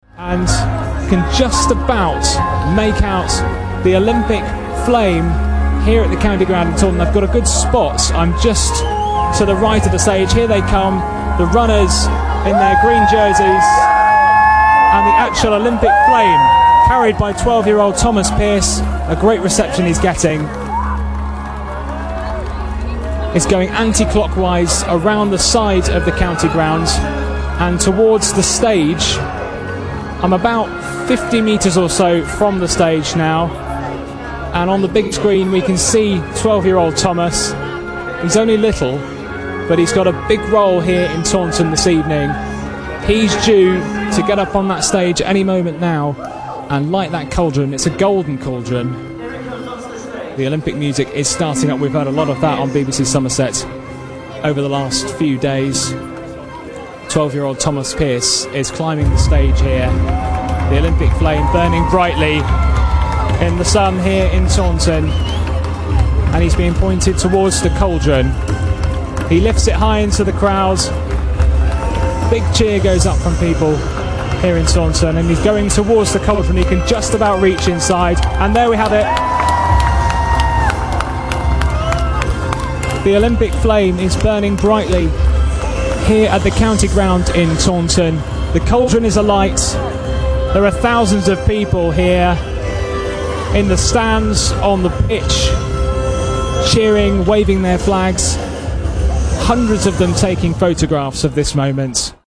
The moment the Olympic cauldron was lit at Taunton's County Ground